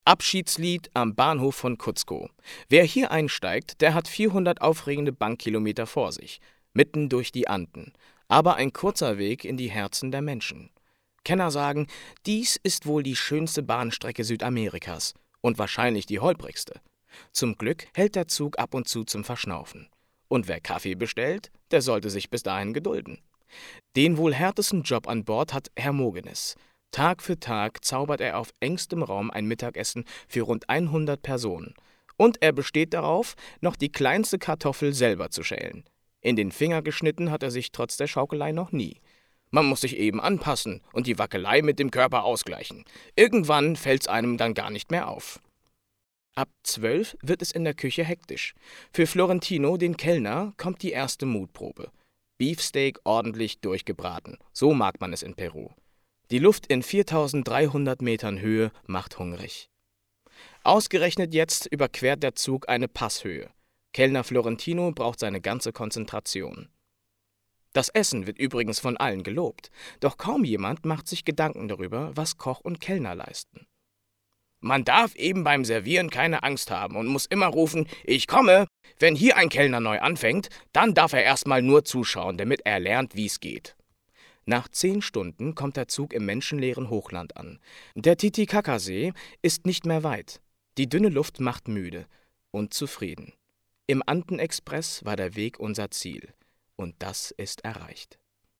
Theaterschauspieler und Sänger
Sprechprobe: Werbung (Muttersprache):